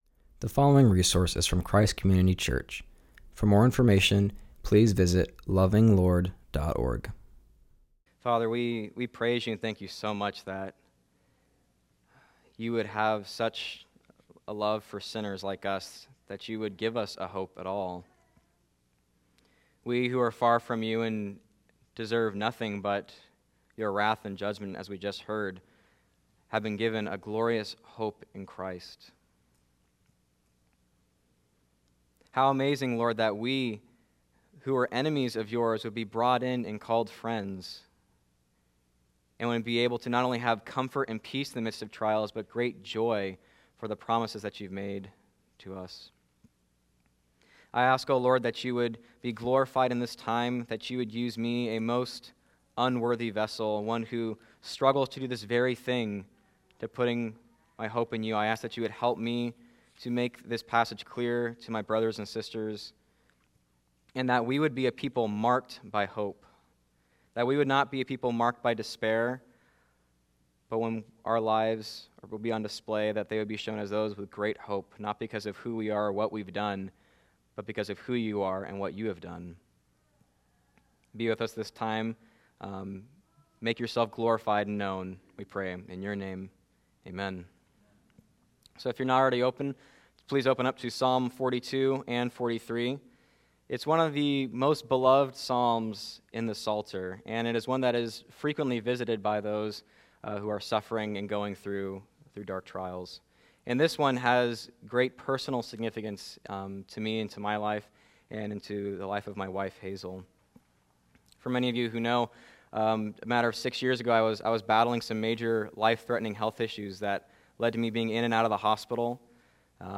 preaches from Psalm 42-43.